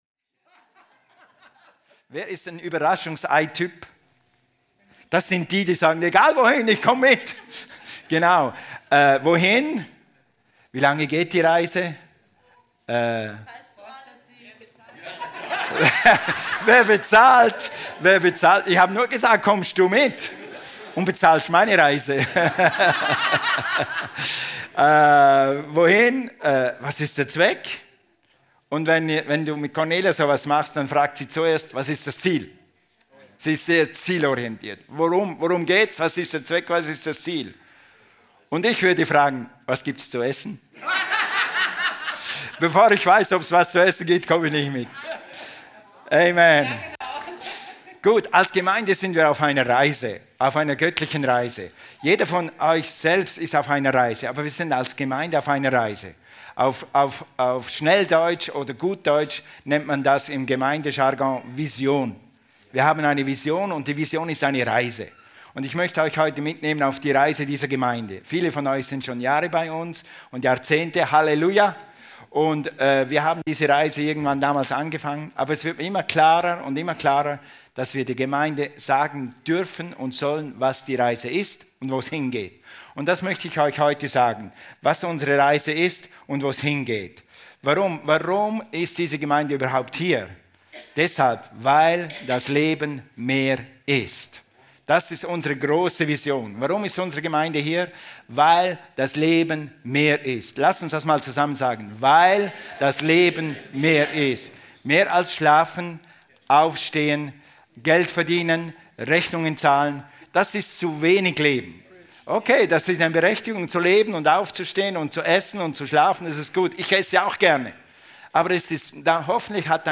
Life unlimited - Vision ~ Predigten vom Sonntag Podcast
Alle Predigten aus den Sonntagsgottesdiensten